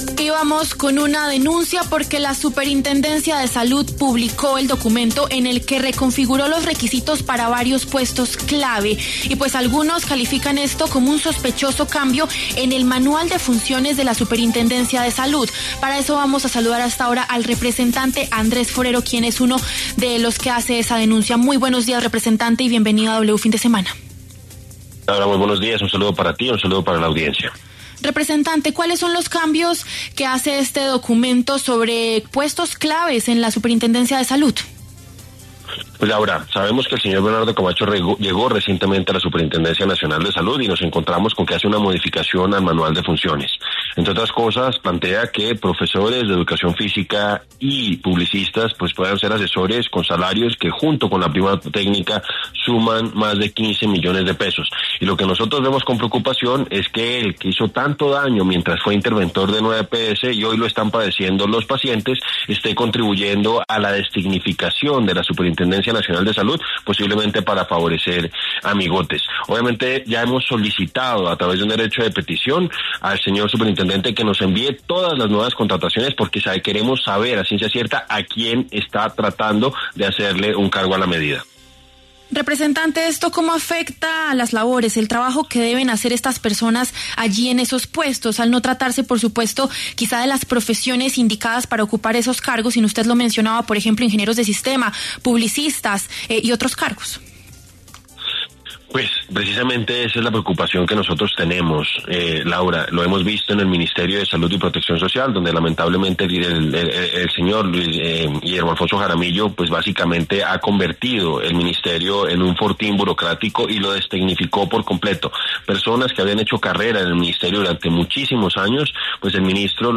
El representante Andrés Forero, del Centro Democrático, dialogó con W Fin De Semana acerca de la reconfiguración de los requisitos para varios puestos clave al interior de la Superintendencia de Salud.